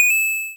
coin.wav